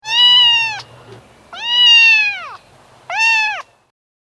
Animals
Cat(70K) -Pig(70K) -